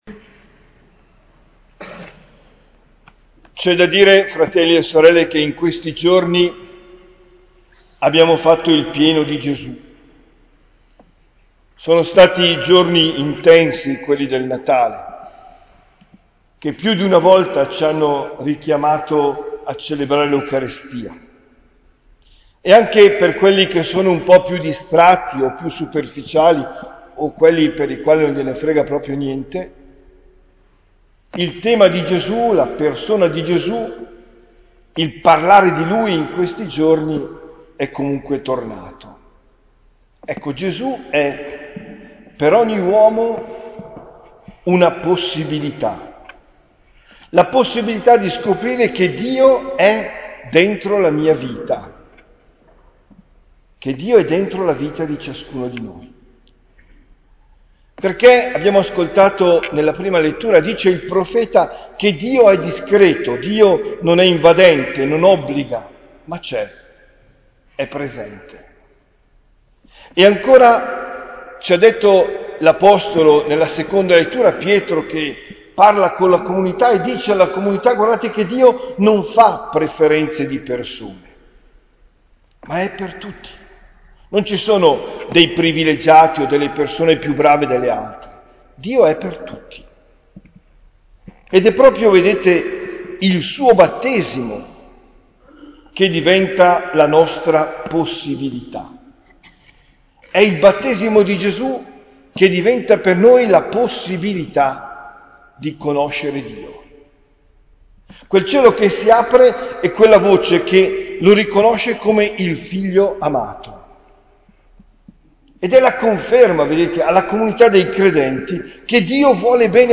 OMELIA DEL 08 GENNAIO 2023